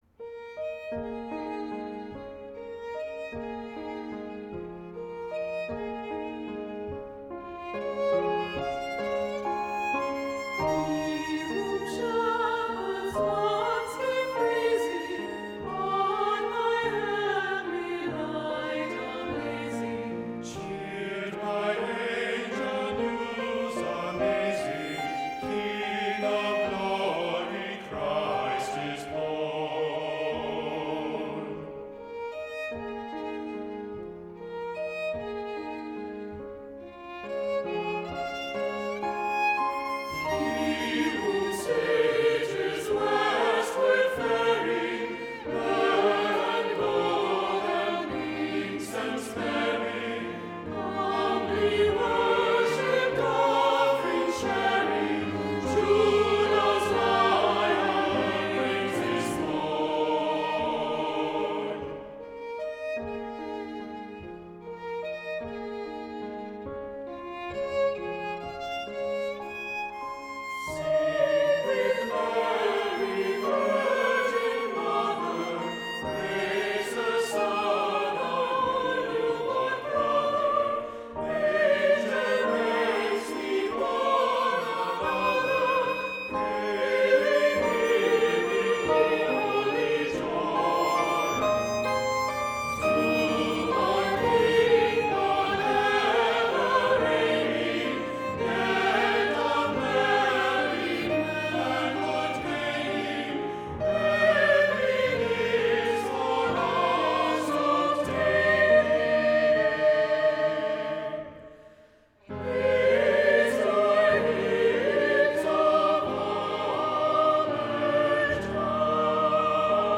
Voicing: "Two-part mixed","Assembly"